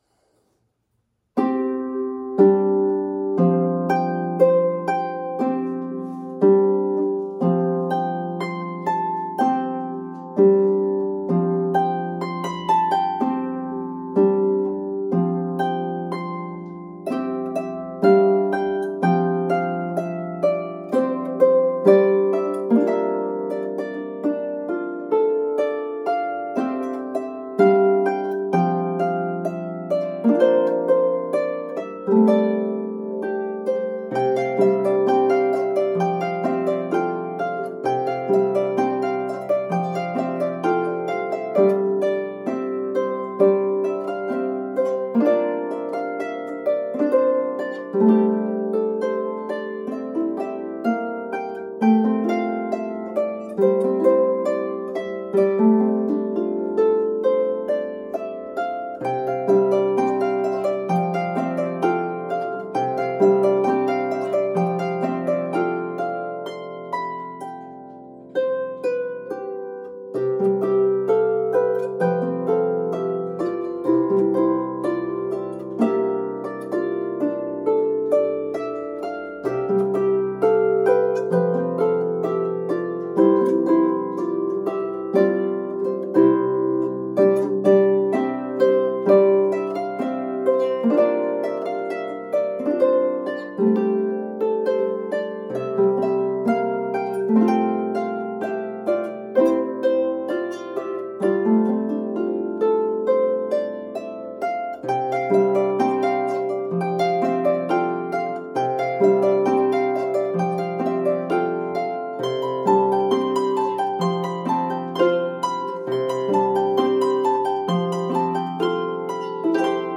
hymn tune
or as an uplifting postlude